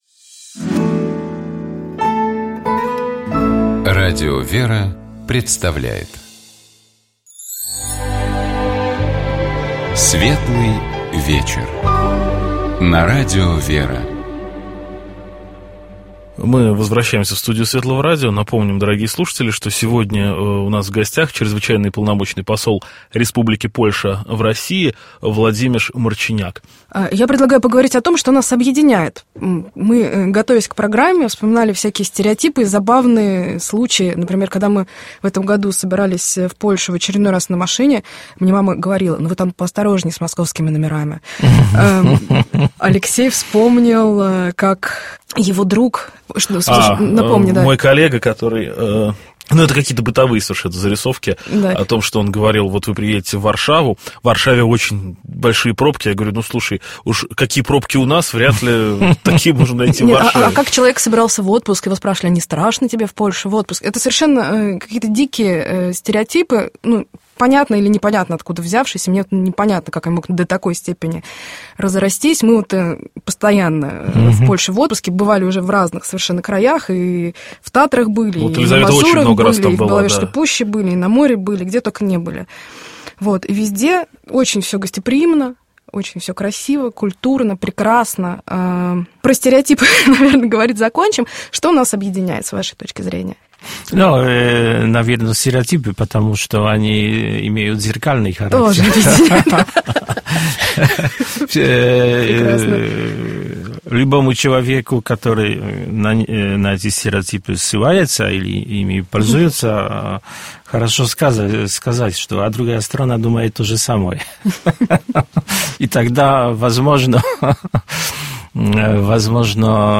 У нас в гостях был Чрезвычайный и Полномочный Посол Республики Польши в России Влодзимеж Марчиняк.